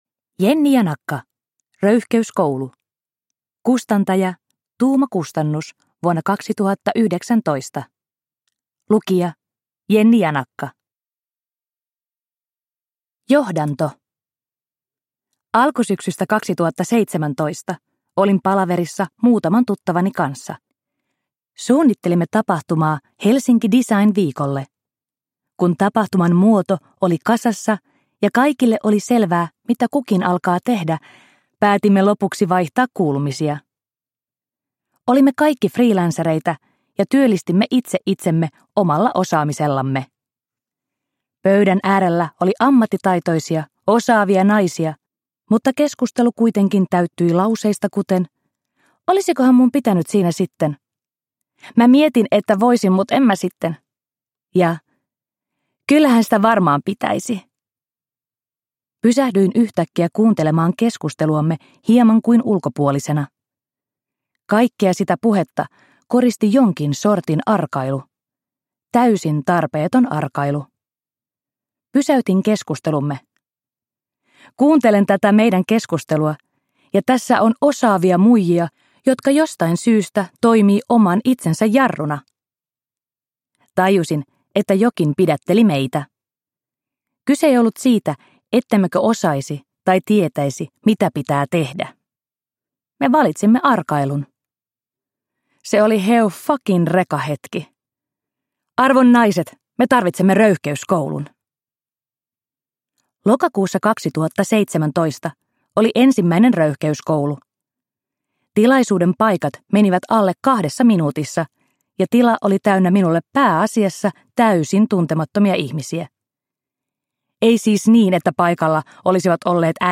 Röyhkeyskoulu – Ljudbok – Laddas ner